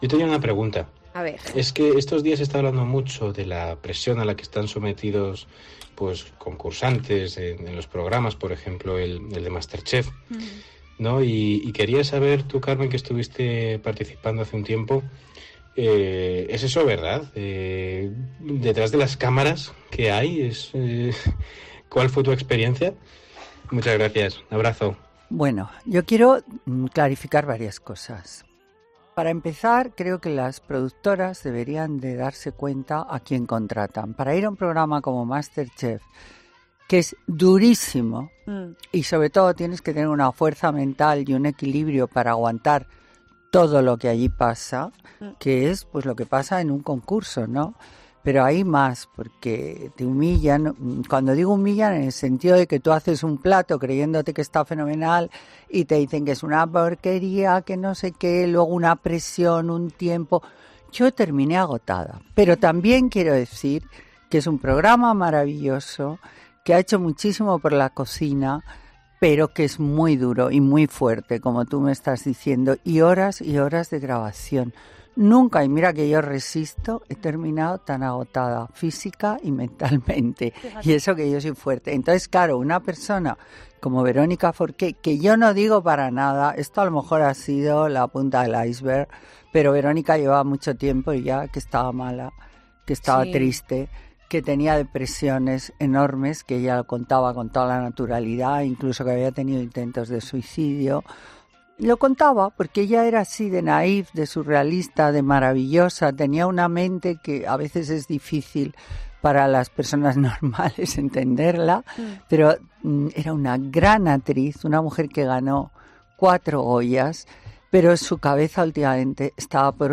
Algo sobre lo que ha querido arrojar luz la socialité y colaboradora de COPE en Fin de Semana, Carmen Lomana: “Yo quiero clarificar varias cosas”, comenzaba el cuestionario tras la pregunta de uno de los oyentes.
En ese momento la presentadora de Fin de Semana, Cristina López Schlichting, se mostraba dura con el programa, al que acusaba de haber tenido “afán de dinero”.